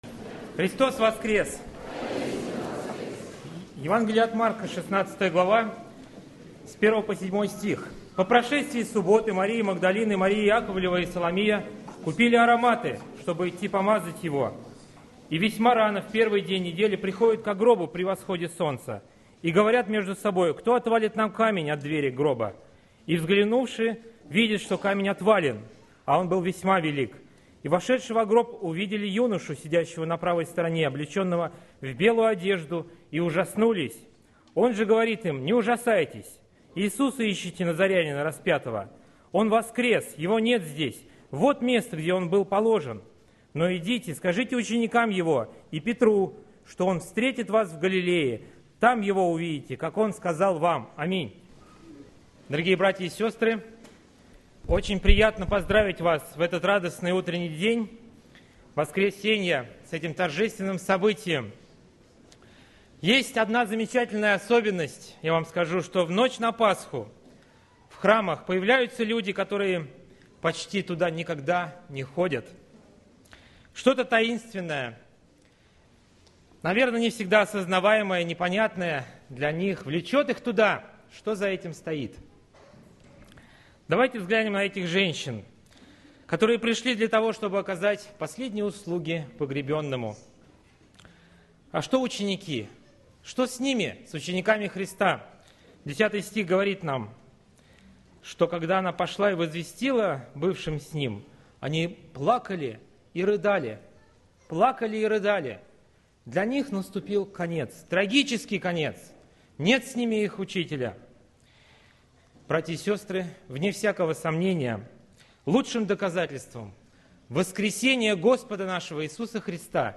Церковь: Московская Центральная Церковь ЕХБ (Местная религиозная организация "Церковь евангельских христиан-баптистов г. Москвы")